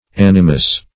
Animus \An"i*mus\, n.; pl. Animi. [L., mind.]